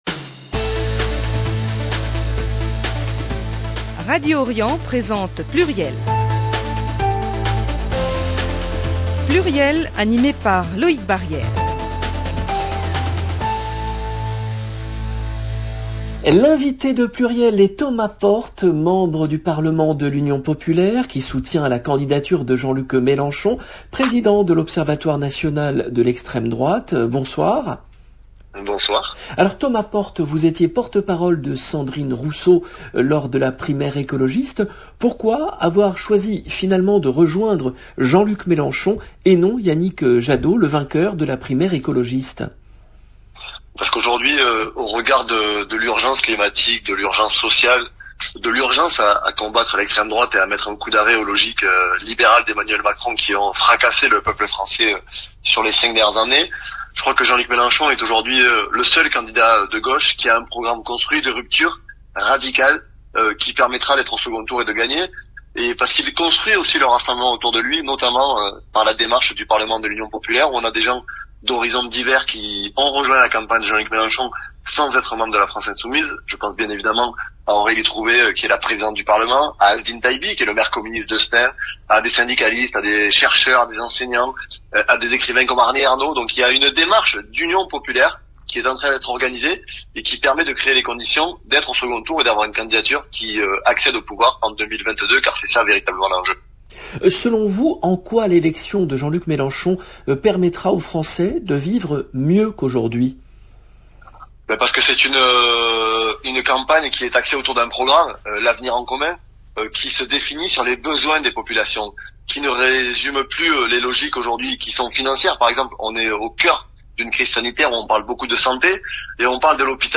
L’invité de PLURIEL est Thomas Portes , membre du Parlement de l’Union populaire qui soutient la candidature de Jean-Luc Mélenchon, président de l’Observatoire National de l’Extrême droite.